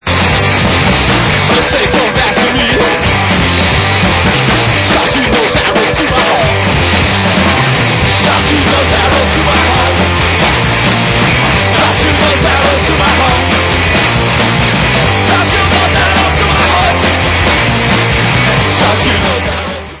sledovat novinky v oddělení Rock/Punk